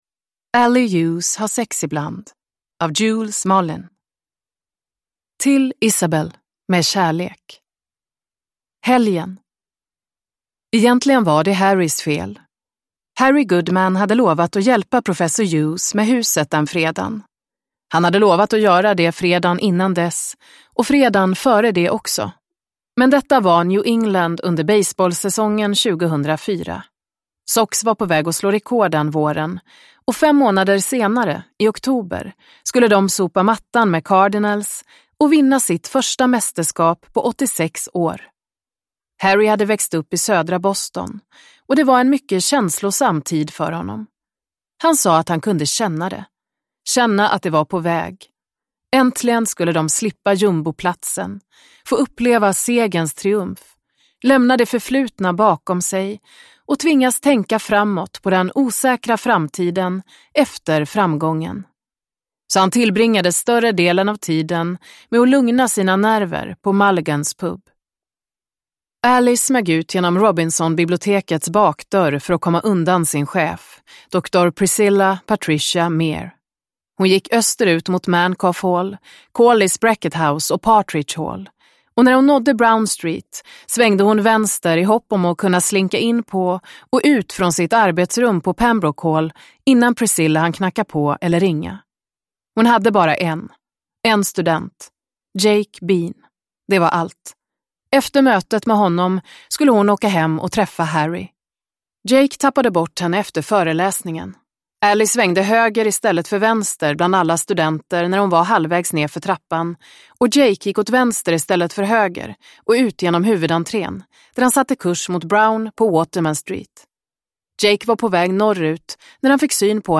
Ally Hughes har sex ibland – Ljudbok – Laddas ner